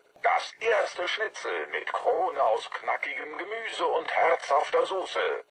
Werbung Prosodie: accent tonique und intonation descendante en fin de phrases déclaratives